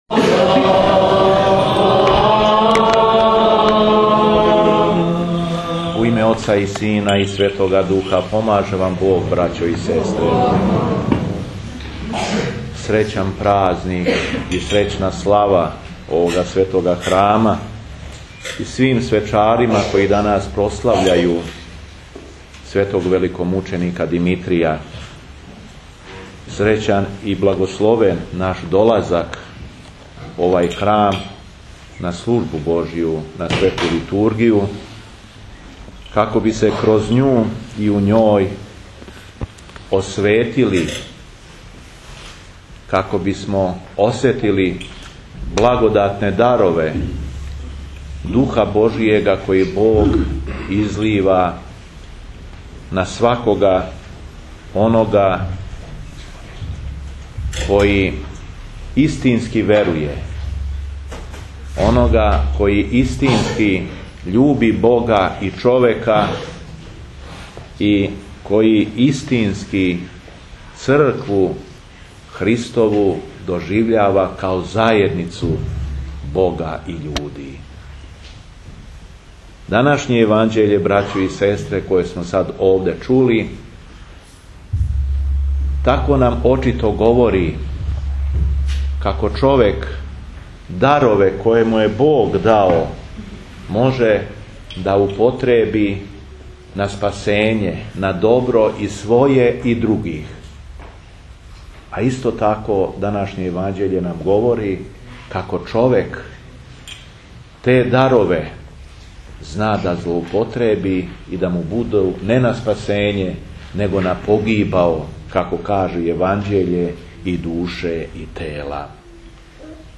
Литургију су улепшала бројна дечица својим певањем.